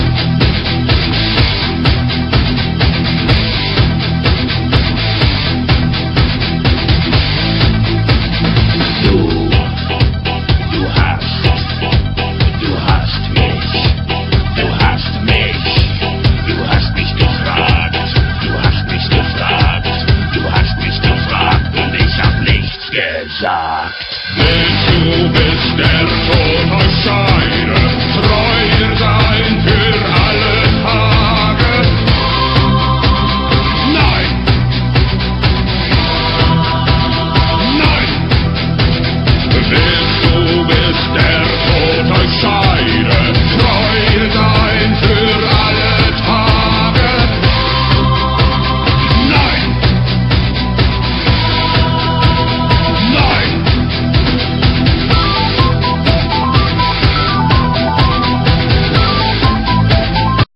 metal
they have a heavy, machine like rythem
and growling vocals.